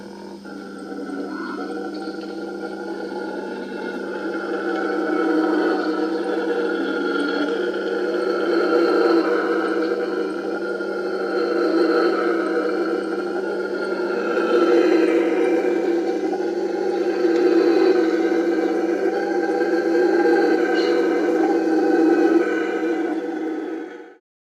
Our first stop in Costa Rica was in the tranquil bay of Santa Elena.
The tranquility soon was broken by this sound
No, just the local howler monkey troop welcoming us to Costa Rica.
This country had me at first grunt.
howlermonkey.wav